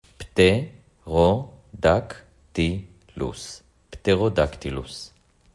פט-רו-דק-טי-לוס